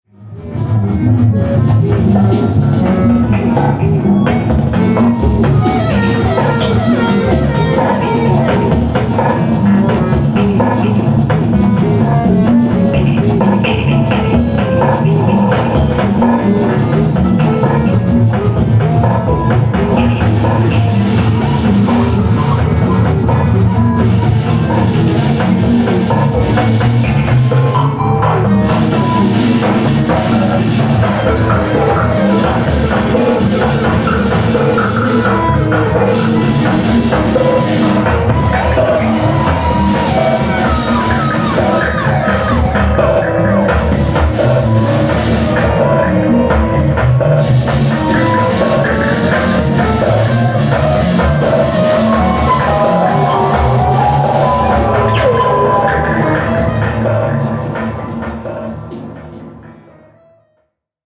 Screening mit Liveset:
accidental guitar
electronics, percussions
accordeon
voice